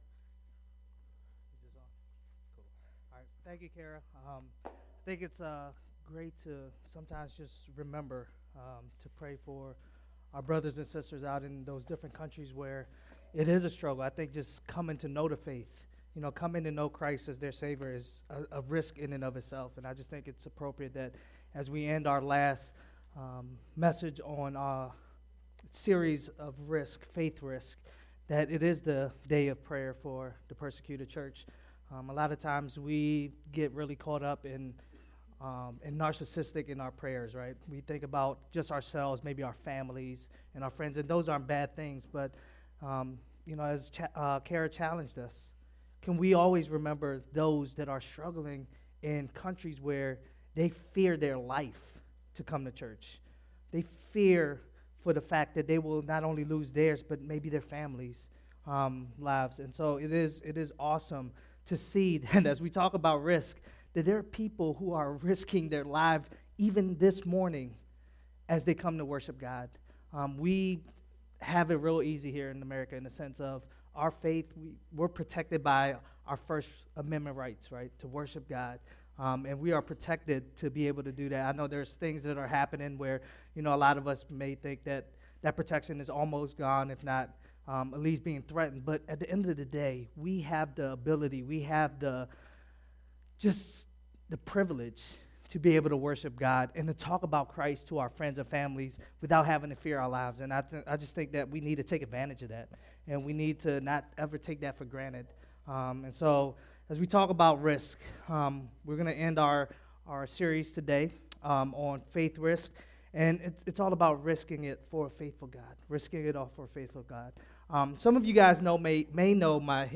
RiSK – new sermon series (Sept/Oct 2014)